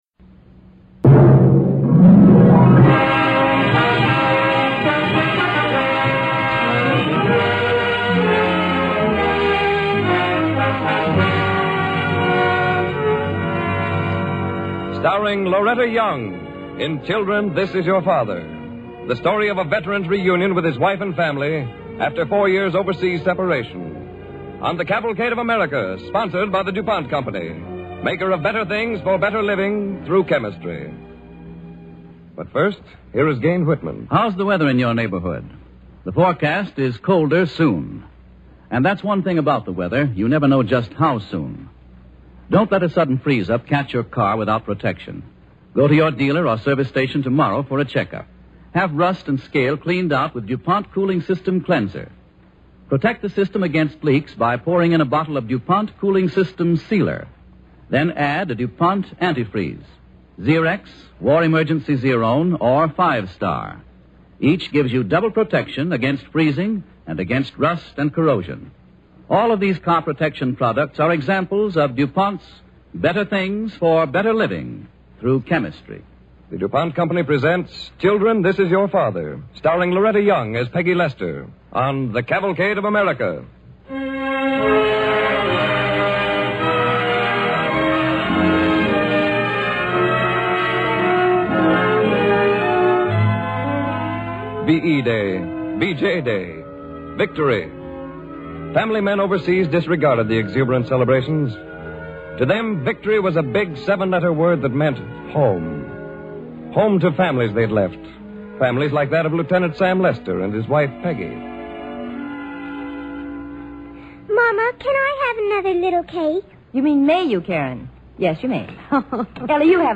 starring Loretta Young and Gale Gordon